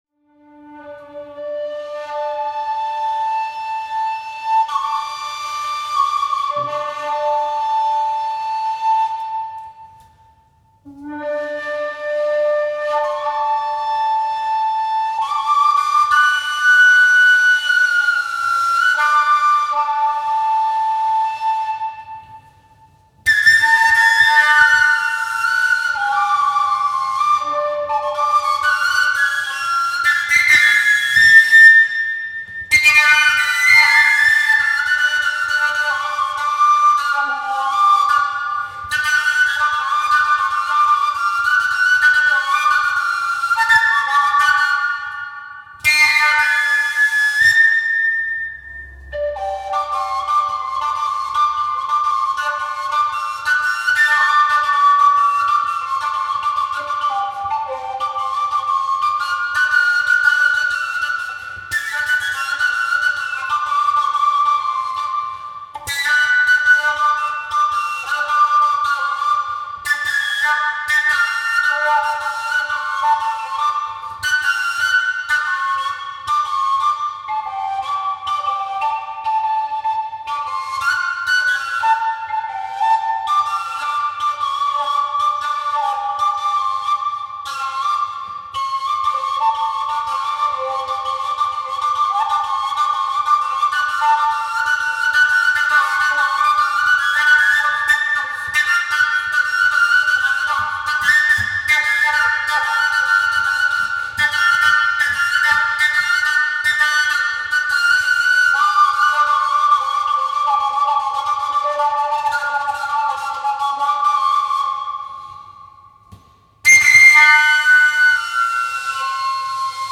お客さんのオーバートーンフルート演奏
オーバートーンフルートは指穴がなくて吹くだけなんですが、人によって演奏スタイルがずいぶん違います。これはフルートが叫んでいるような激しい曲。
ちなみにきもちのよい反響音は、風呂場で吹いて録音したんだそうです。